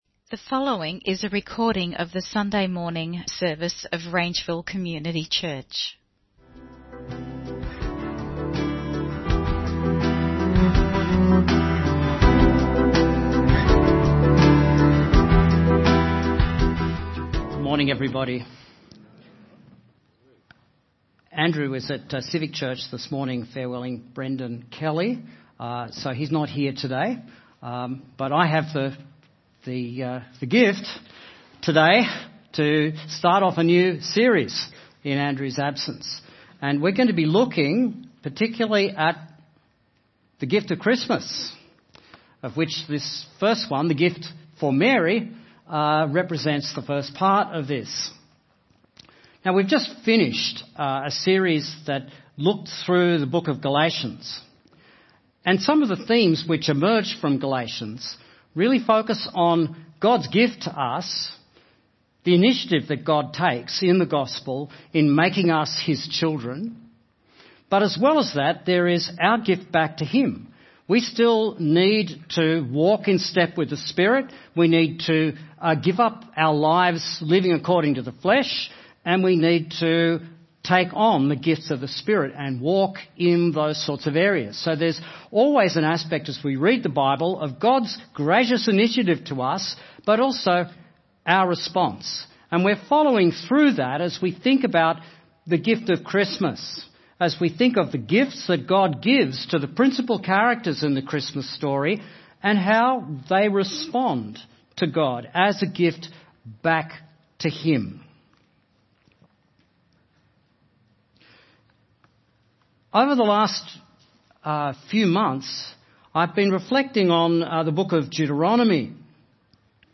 The Christmas Gift for Mary (Sermon Only - Video + Audio)